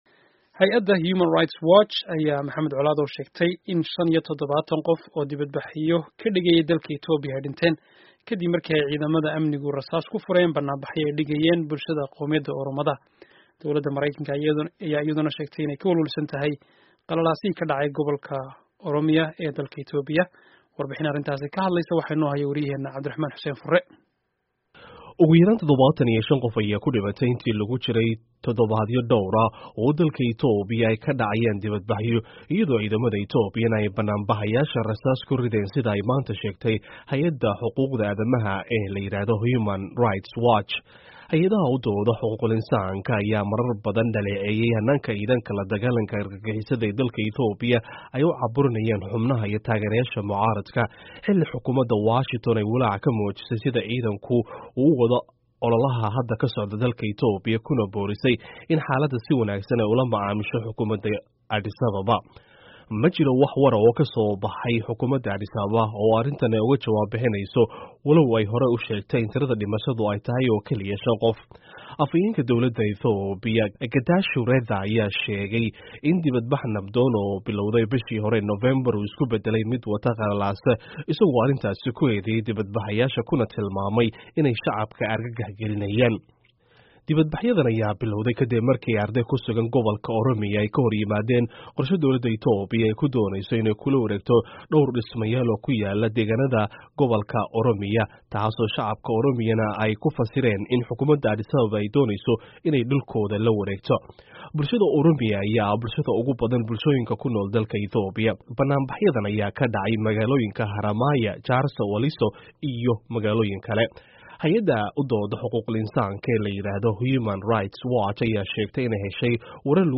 Warbixinta Xaaladda Itoobiya